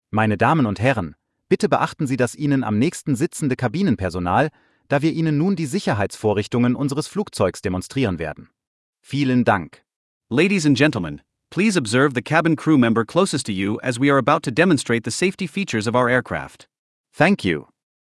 PreSafetyBriefing.ogg